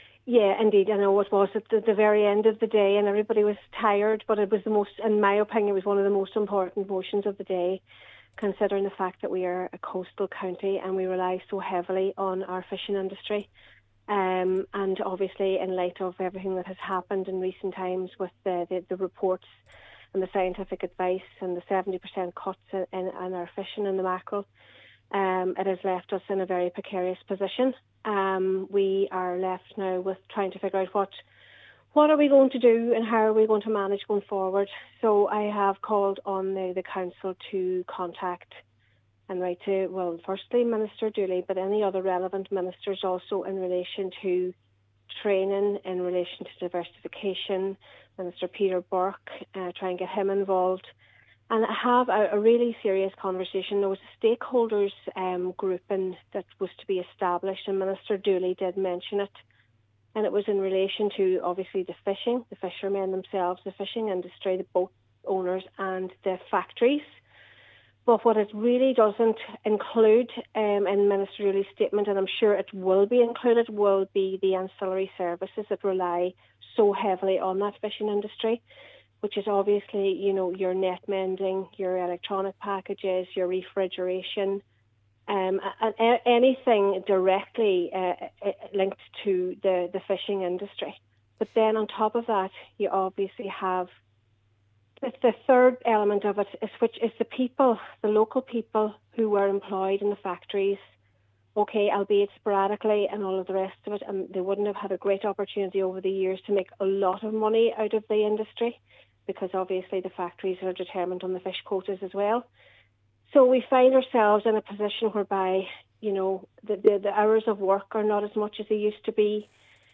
Cllr Kennedy says tertiary supports must also be put in place for employees who are out of pocket due to the difficulties facing coastal communities.